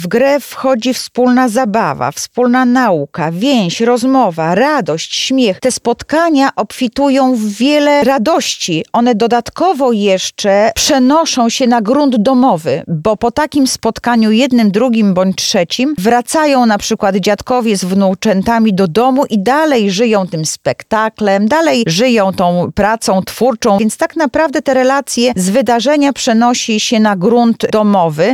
Korzystając z tego typu wydarzeń, widzimy że pewne bariery pokoleniowe zacierają się – mówi Anna Augustyniak, Zastępca Prezydenta Lublina ds. Społecznych: